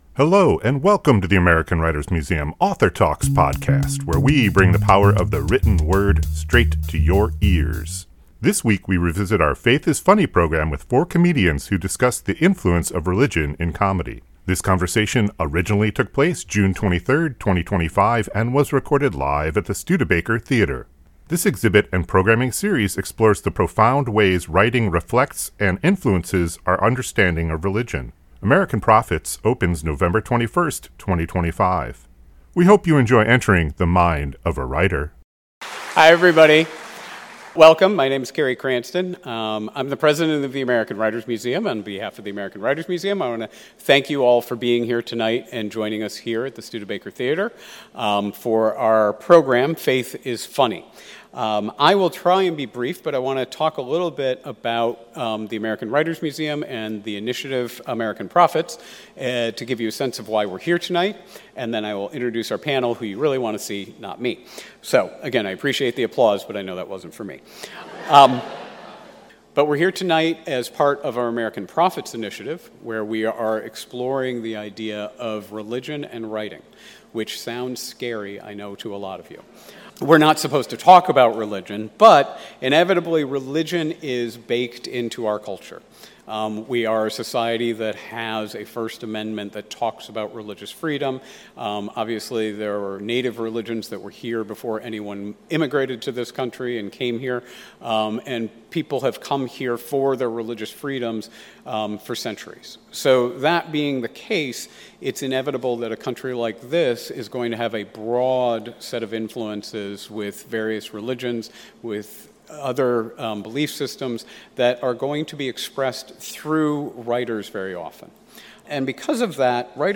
This conversation originally took place June 23, 2025 and was recorded live at the Studebaker Theater.